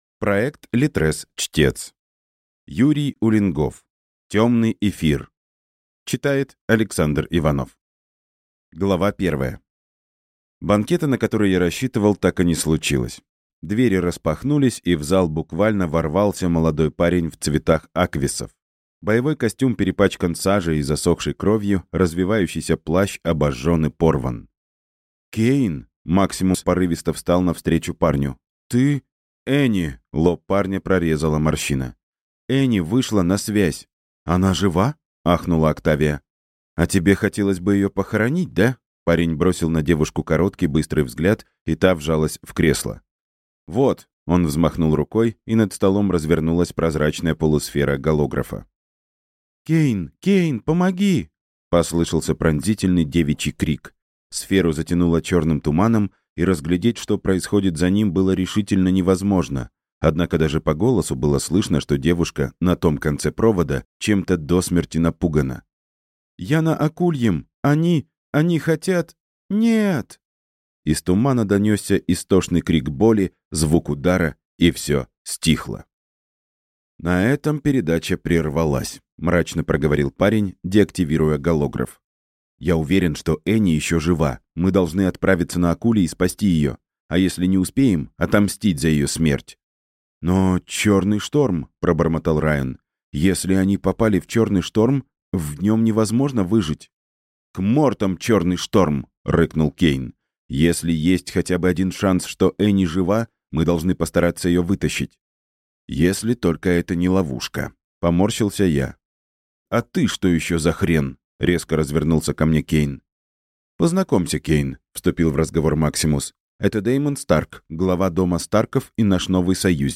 Графиня замка Ривердон (слушать аудиокнигу бесплатно) - автор Надежда Игоревна Соколова